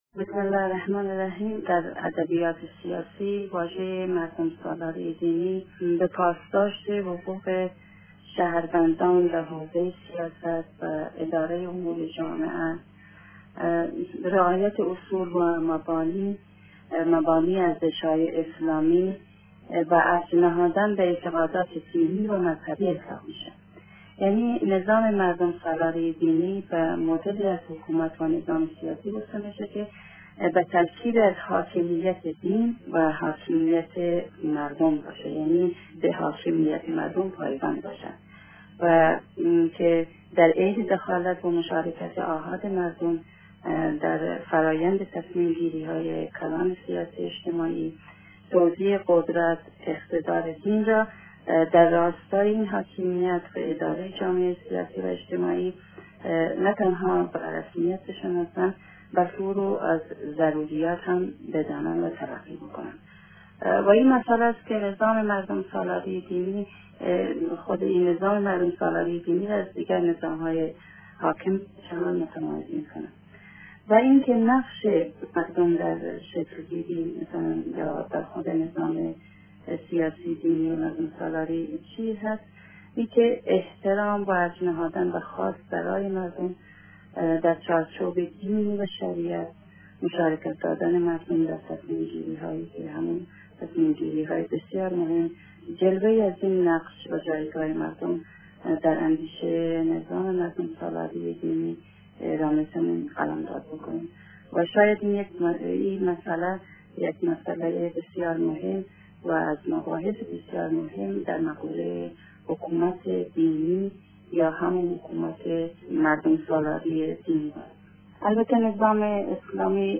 کارشناس سیاسی-مذهبی افغان تاکید کرد: در نظام مردمسالاری دینی رای و نظر مردم از جایگاه و اهمیت ویژه ای برخوردار است.